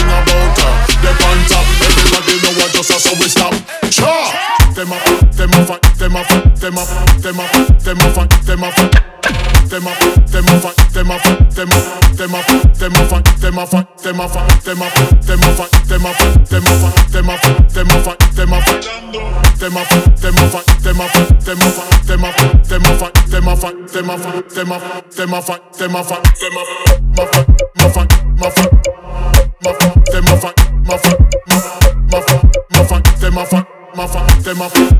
Жанр: Танцевальные / Регги
Reggae, Dance